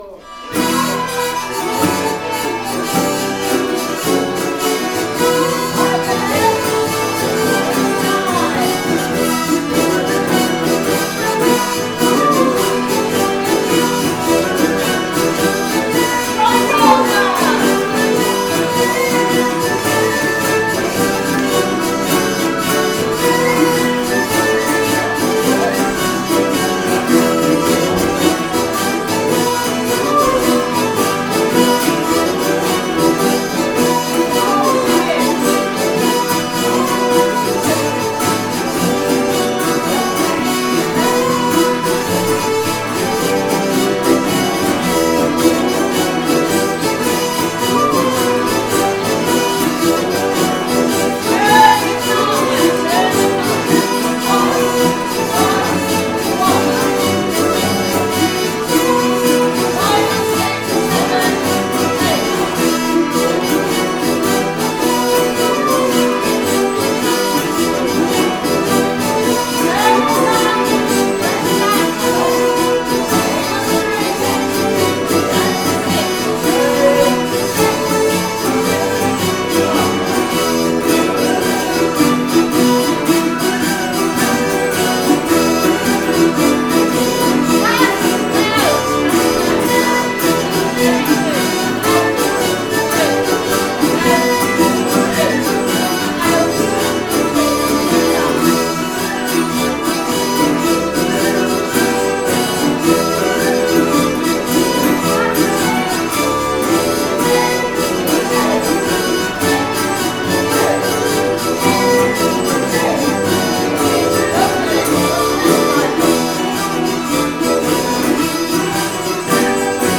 Please excuse the quality as they were recorded live in practice sessions, not in a recording studio!
Two Hurdy Gurdy’s, Kaval, Violin, Bassoon, Octave Mandolin, Pipes, Bouzouki, Melodeons , Guitar and Djembe.